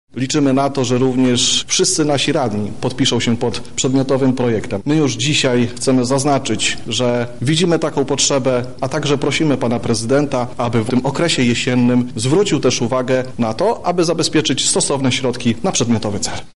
Inicjatywa jest zupełnie apolityczna – zapewnia radny Prawa i Sprawiedliwości Piotr Popiel: